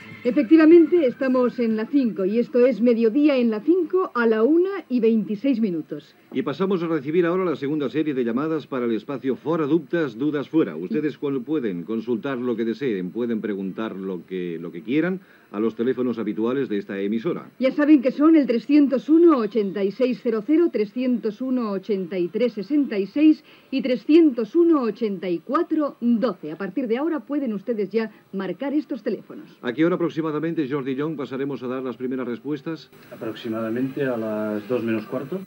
Identificació del programa i espai "Fuera dudas" amb els telèfons de participació
Entreteniment
Extret del programa de TVE a Catalunya "Temes d'avui" emès el 4 d'abril del 1983